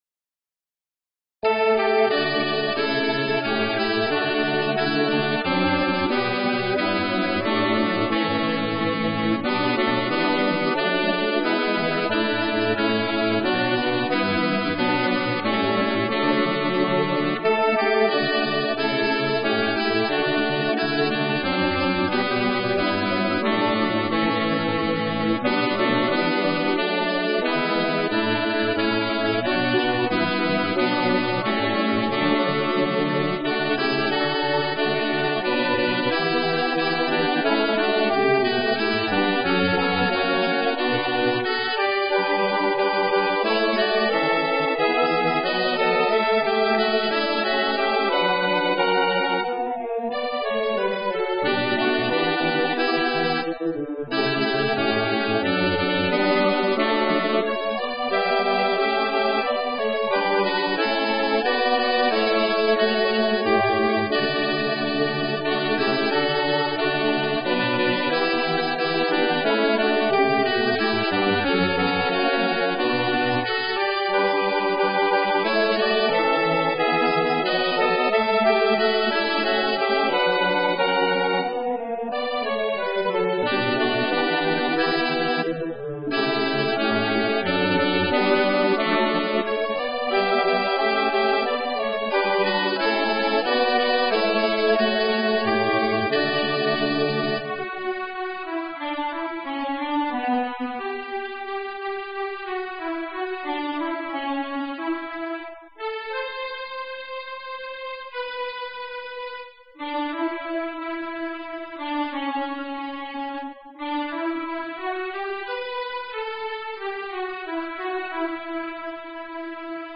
Makundi Nyimbo: Anthem | Mafundisho / Tafakari